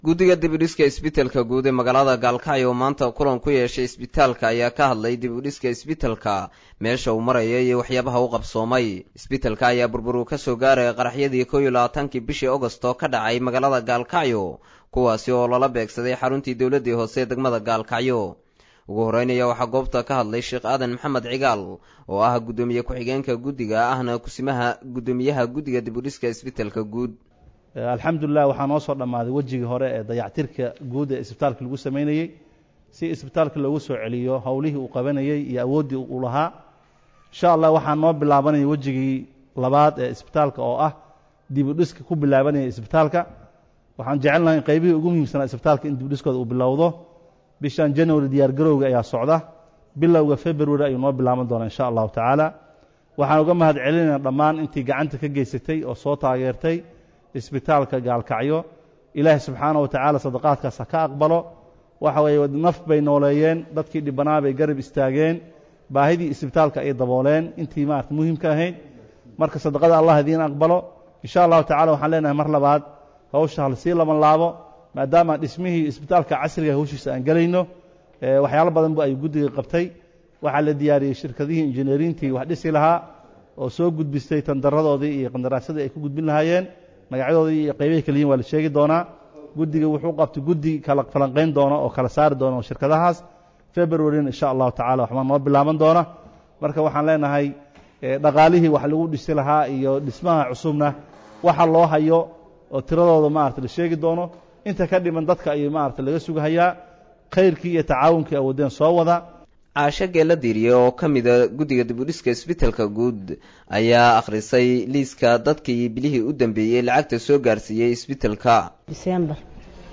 Guddiga dib u dhiska Cisbitalka guud Gobolka Mudug ee magaalada Galkacyo oo maanta warbaahinta kula hadlay magaalada Galkacyo ayaa ka warbixiyey halka uu mariyo dhismaha cisbitalkaasi, lacagaha soo gaaray iyo Qorshahooda.